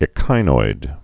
(ĭ-kīnoid)